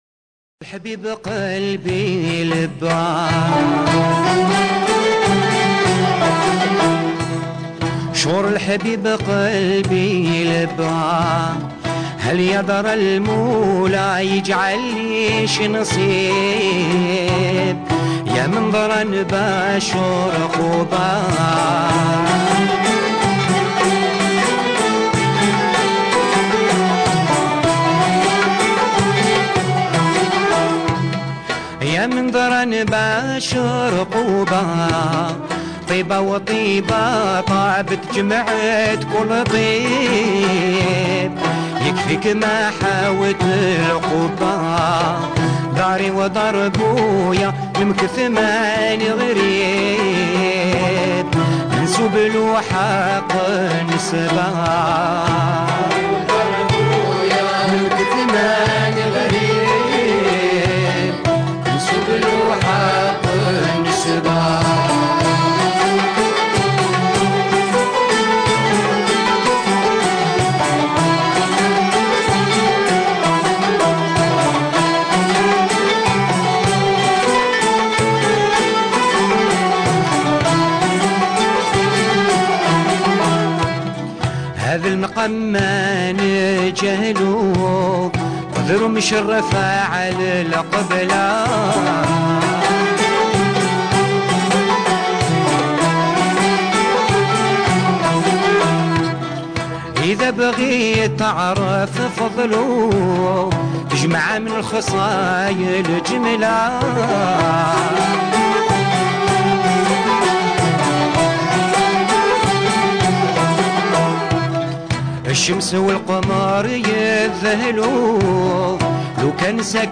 -1- طرب غرناطي
Gharnati_enfant_1.mp3